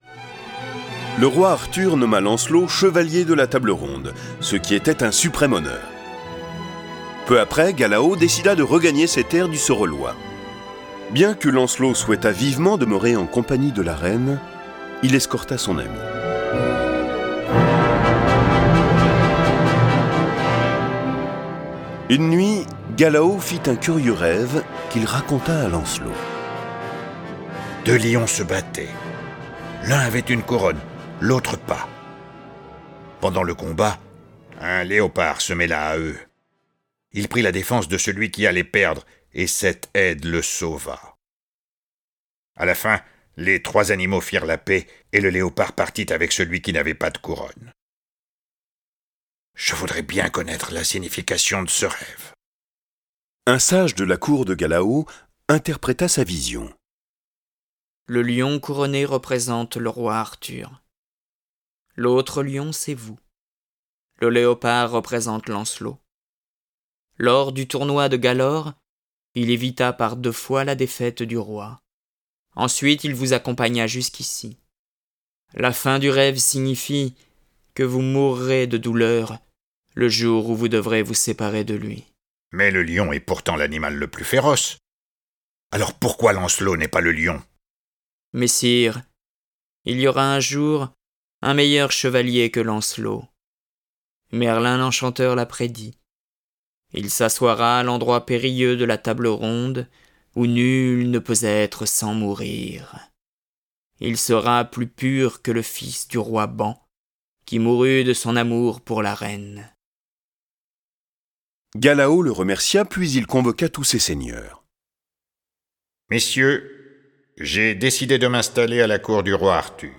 Diffusion distribution ebook et livre audio - Catalogue livres numériques
2004-10-01 Ecoutez la merveilleuse histoire de Lancelot du Lac, cet enfant élevé par la mystérieuse fée Viviane, devenu ensuite un véritable héros à la cour du Roi Arthur. Sous les yeux admiratifs de la belle Guenièvre, il multiplie les exploits, pourchasse les traîtres et les adversaires du Royaume et accède au suprême honneur de siéger parmi les Chevaliers de la Table Ronde. Le récit sonore de l'aventure de Lancelot est animé par plusieurs voix et accompagnée de plus de trente morceaux de musique classique.